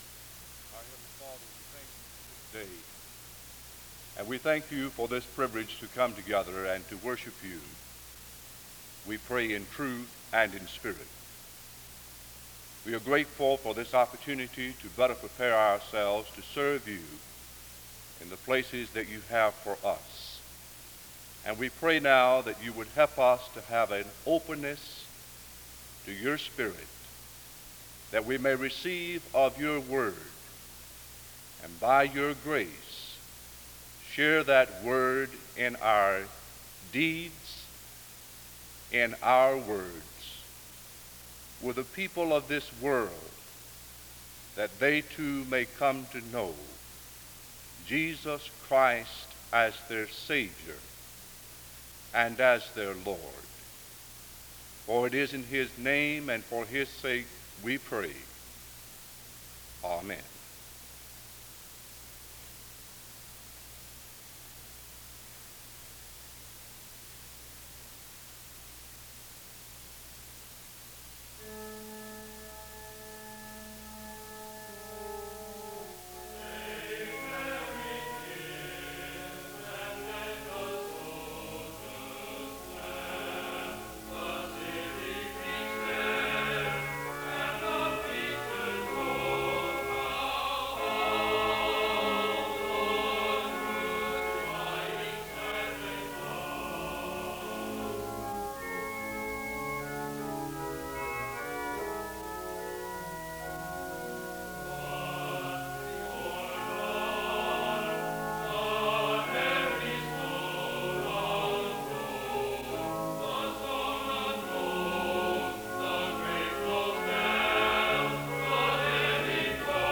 The service begins with a prayer (0:00-1:00). After which, there is a period of singing (1:01-3:42).
The service closes in benediction and prayer (25:46-26:26).